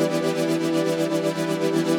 SaS_MovingPad01_120-E.wav